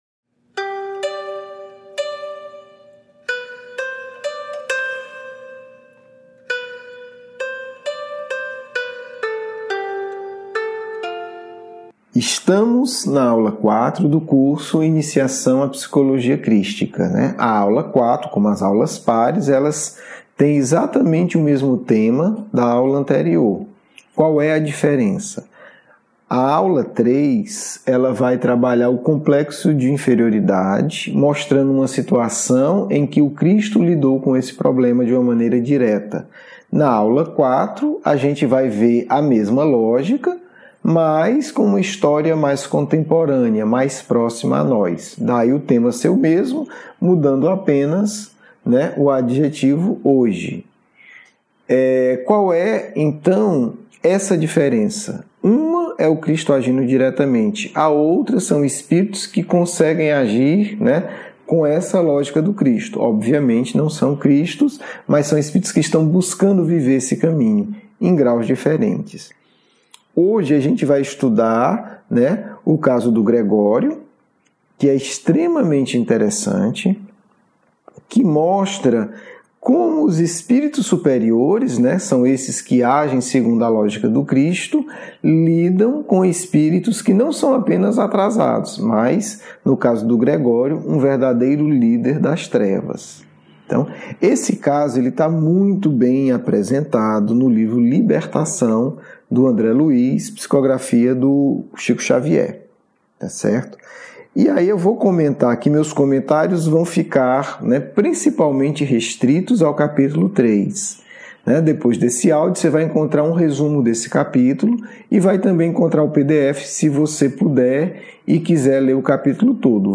IPC-AULA-4-EXPOSICAO.mp3